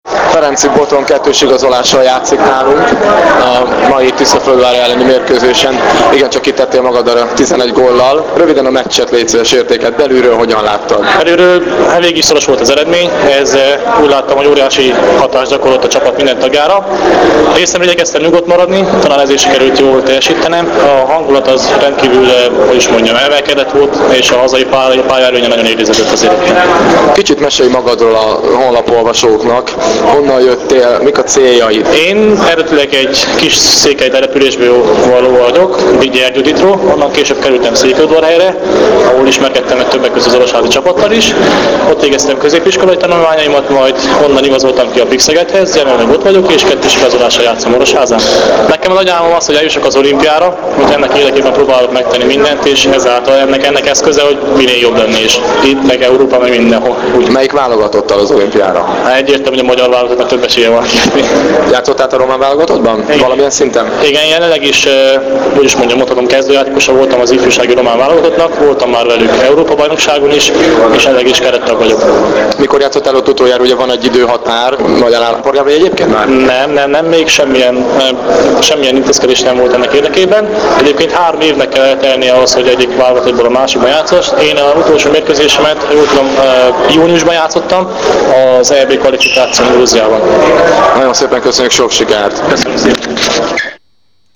Beszélgetés új játékosunkkal